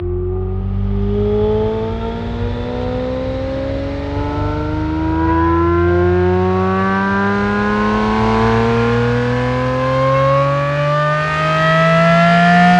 v12_06_accel.wav